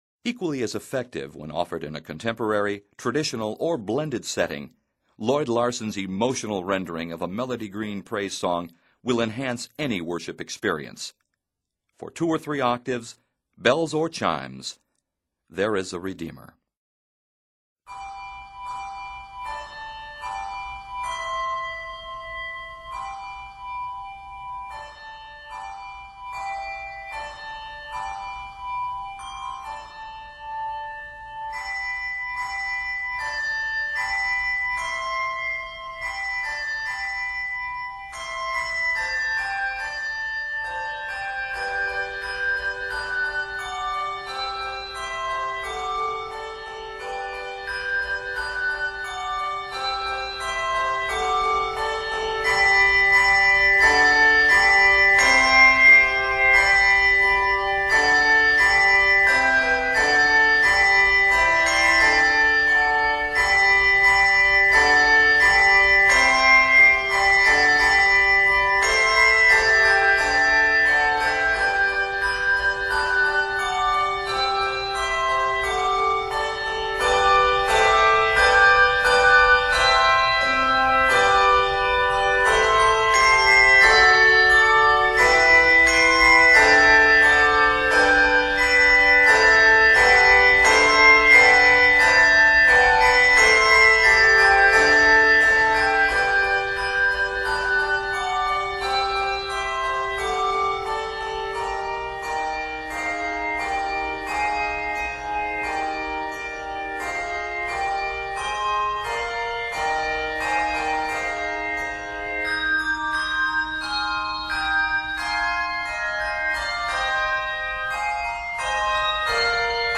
is arranged in G Major and Eb Major